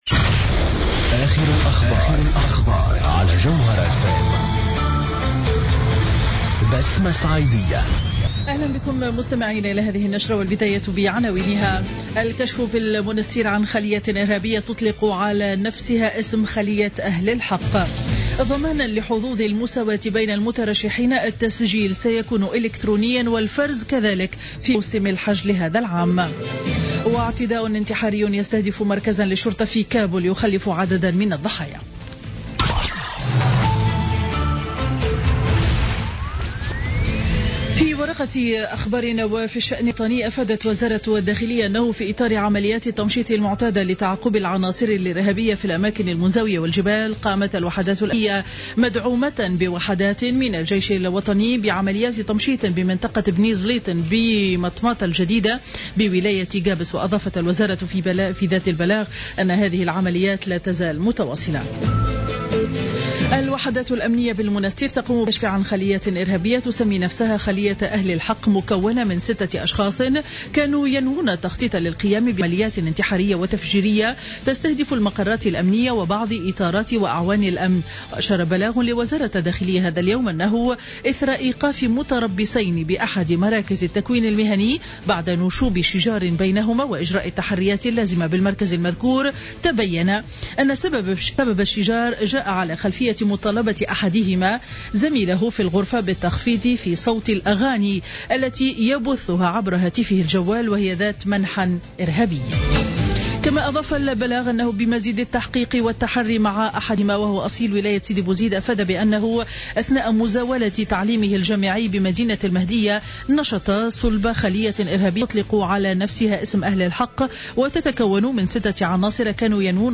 Journal Info 12h00 du lundi 01 février 2016